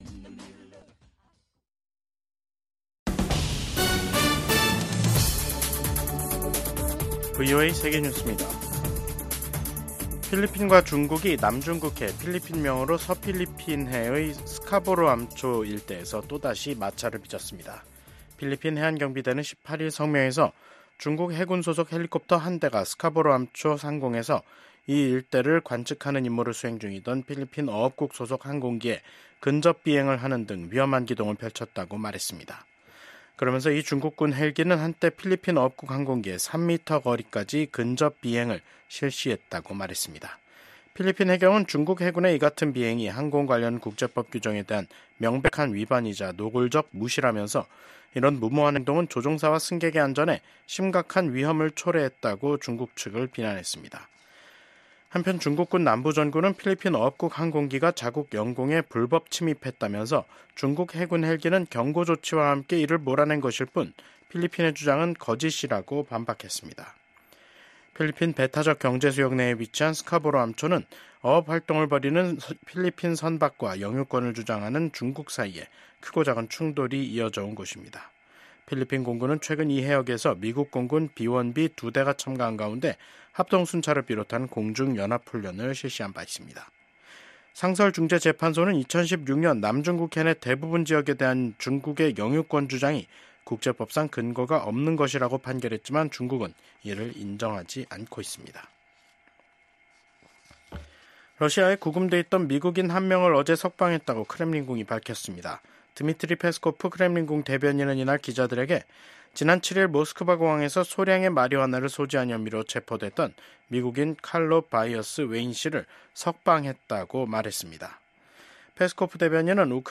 VOA 한국어 간판 뉴스 프로그램 '뉴스 투데이', 2025년 2월 18일 3부 방송입니다. 미국 정부가 북한의 핵·미사일 위협을 비판하며 한국, 일본 등 동맹과 긴밀히 협력하고 있다고 밝혔습니다. 한국이 유엔 안보리 회의에서 러시아에 병력을 파병한 북한을 강하게 규탄했습니다. 북한은 미한일 외교장관들이 북한의 완전한 비핵화를 명시한 공동성명을 발표한 데 대해 반발하는 담화를 냈습니다.